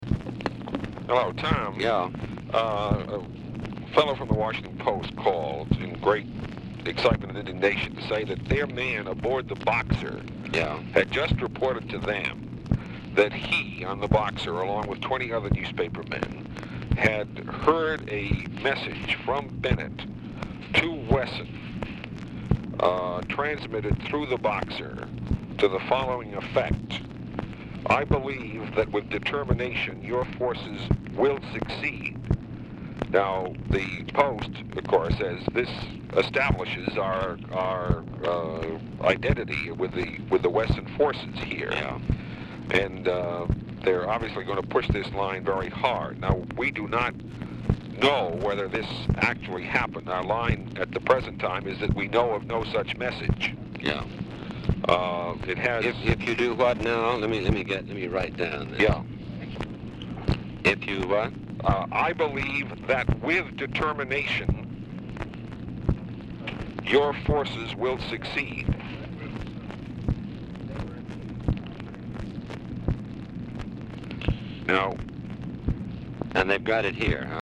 Telephone conversation
RECORDING ENDS BEFORE CONVERSATION IS OVER
Dictation belt